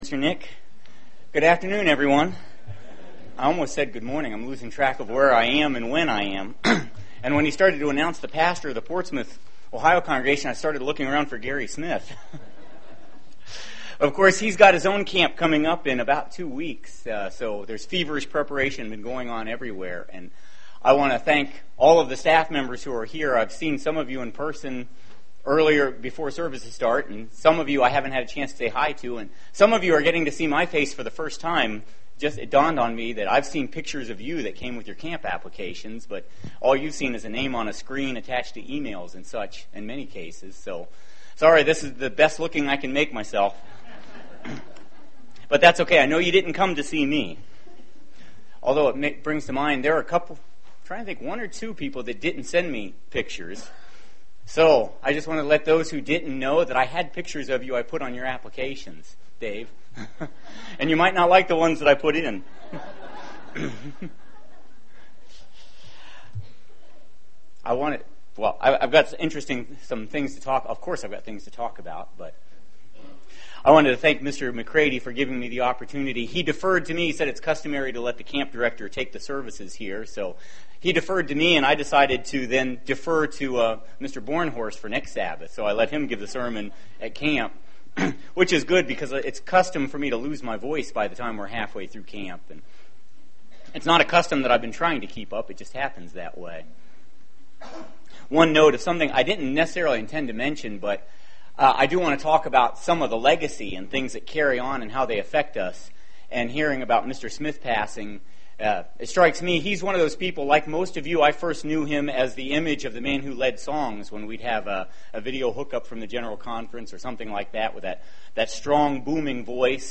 Being called now, we are to be the "Early Crew" to prepare for the coming Kingdom of God and those who will learn of God's way during the Millennium. This sermon was given in preparation for summer camp.